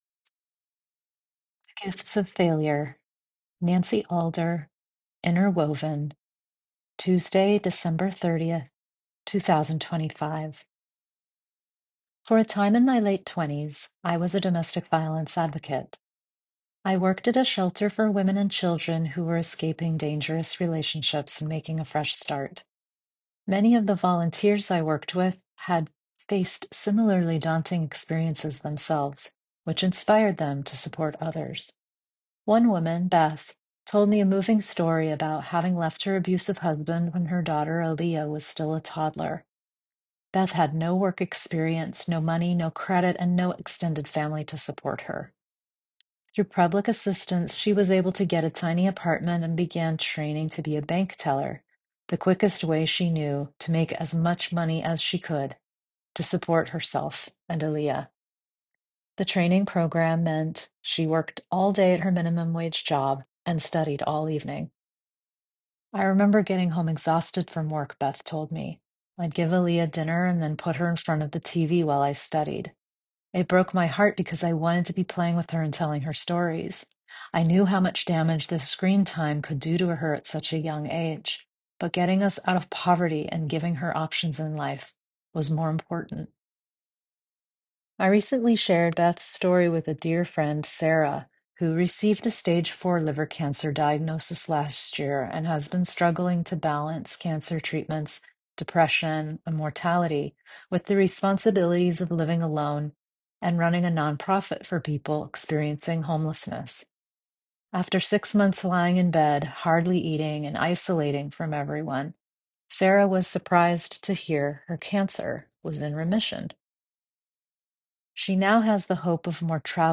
Enjoy this 6 1/2 minute read, or let me read it to you here